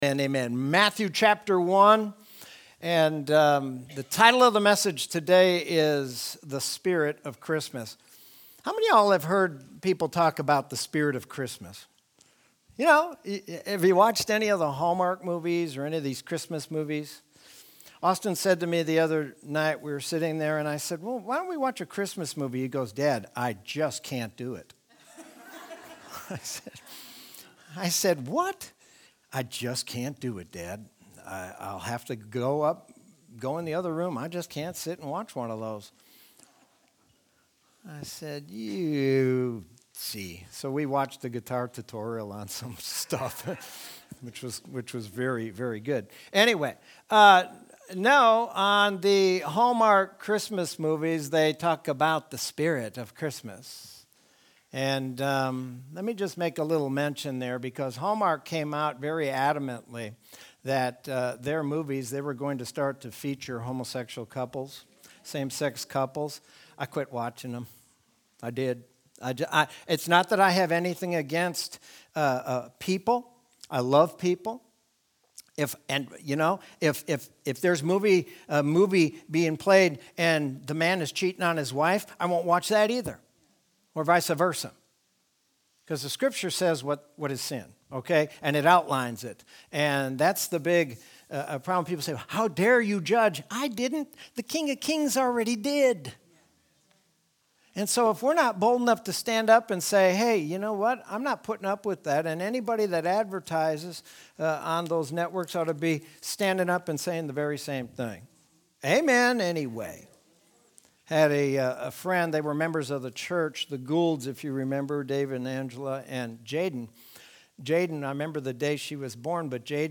Sermon from Sunday, December 20th, 2020.